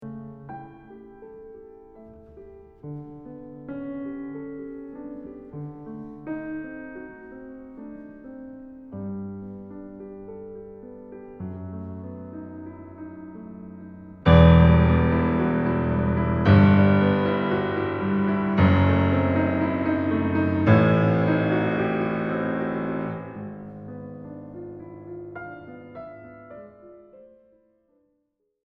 He plays a section that sounds somehow sweeter, and then chocks us with the bass-line theme played in fortissimo: